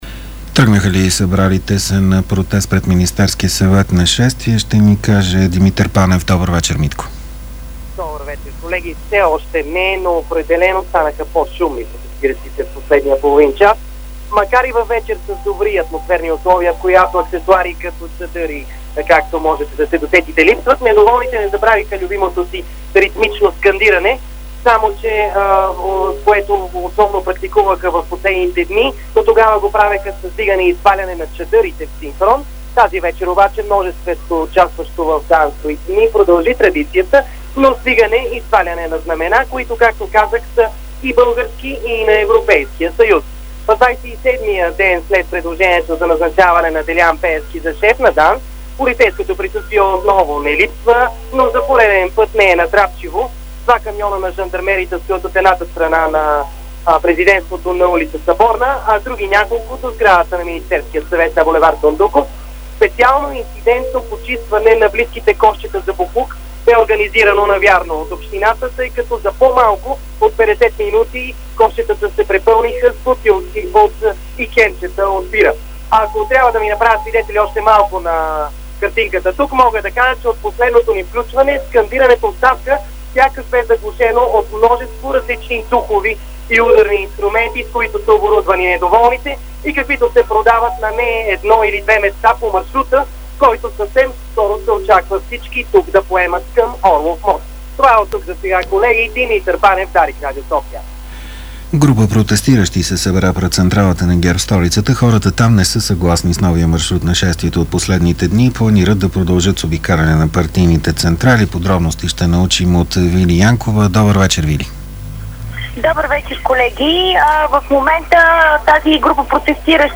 Атмосфера от протеста в София